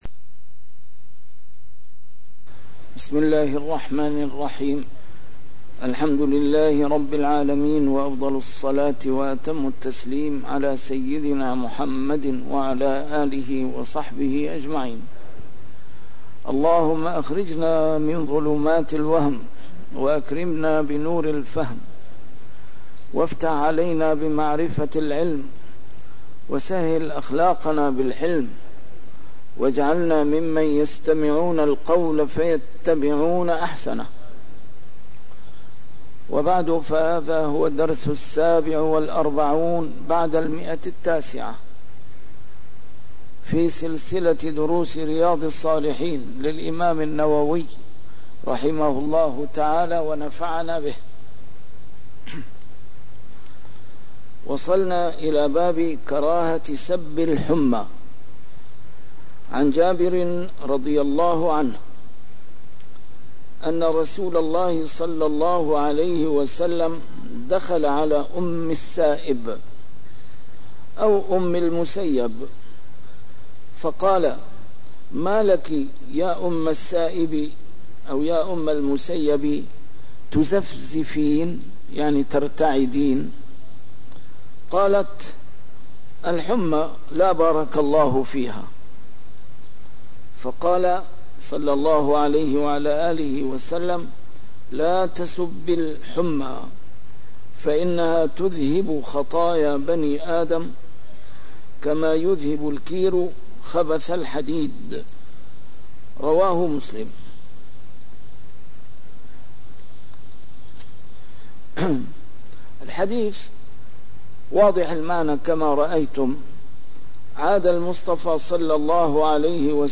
A MARTYR SCHOLAR: IMAM MUHAMMAD SAEED RAMADAN AL-BOUTI - الدروس العلمية - شرح كتاب رياض الصالحين - 947- شرح رياض الصالحين: كراهة سب الحمى - النهي عن سب الريح